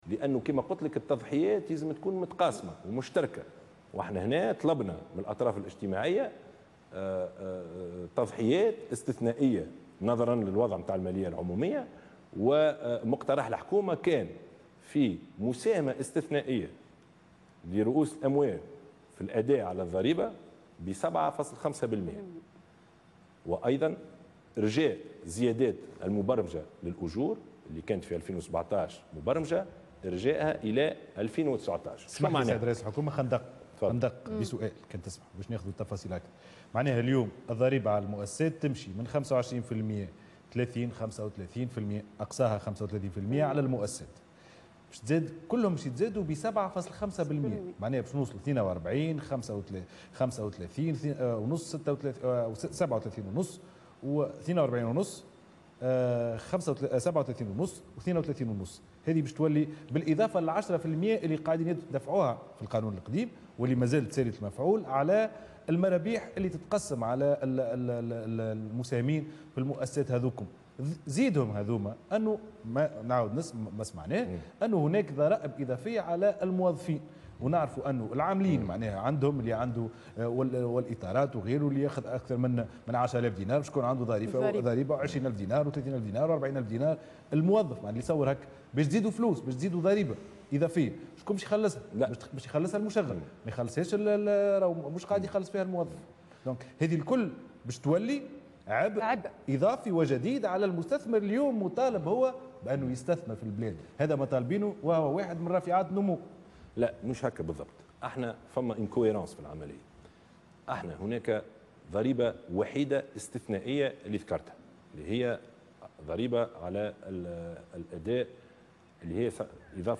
اقترح رئيس الحكومة يوسف الشاهد خلال حوار بثته القناة الوطنية الأولى مساء اليوم الأربعاء 28 سبتمبر 2016 إرجاء الزيادات في الأجور إلى سنة 2019.